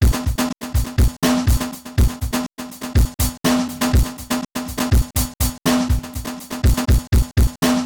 DrumLoop03.wav